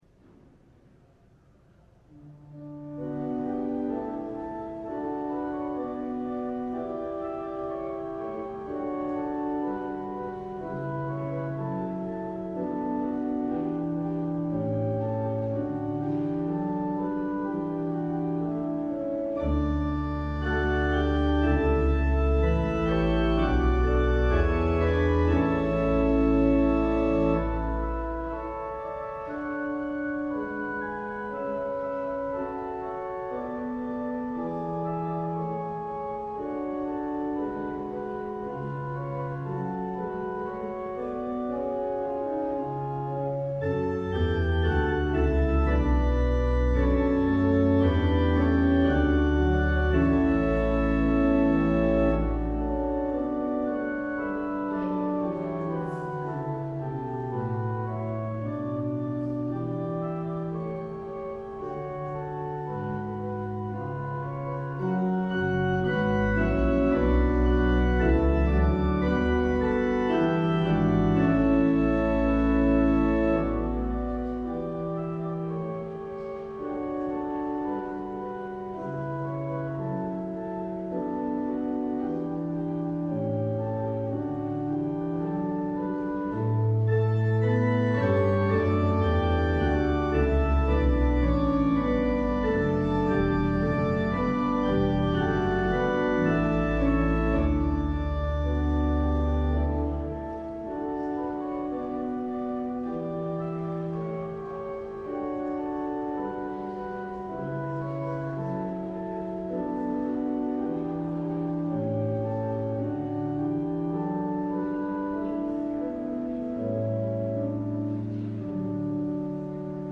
LIVE Evening Worship Service - Resurrection Risk